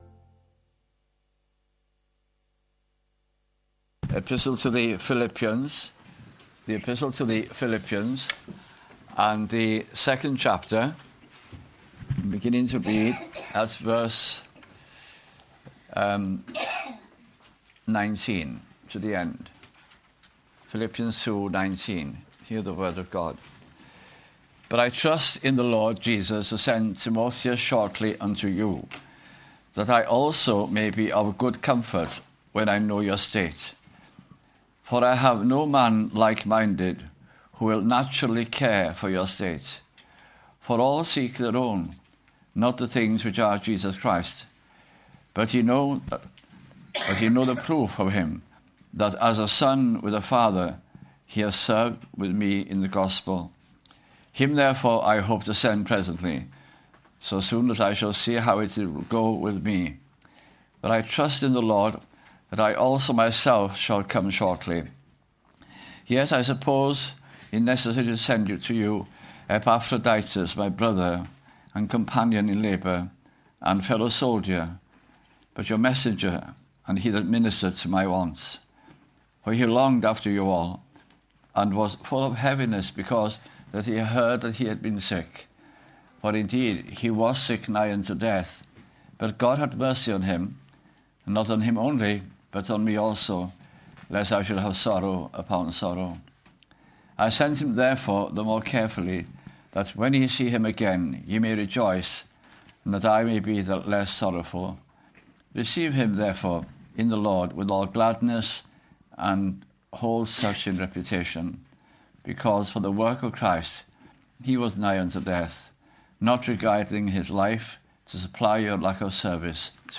» Philippians » Bible Study Series 2008 - 2009 » at Tabernacle Cardiff in the Heath Citizens Hall and then at Pen-y-wain Road, Roath